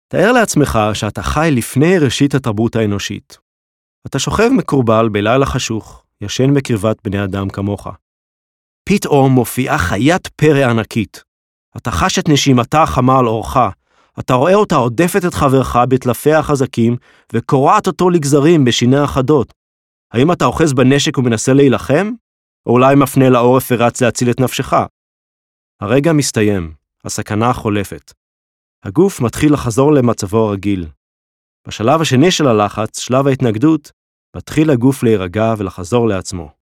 English and Hebrew speaker, Baritone, Narration, Commercials, Character work, NYC voice overs
Sprechprobe: eLearning (Muttersprache):